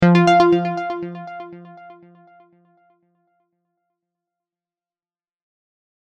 Scifi 2.mp3